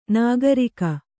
♪ nāgarika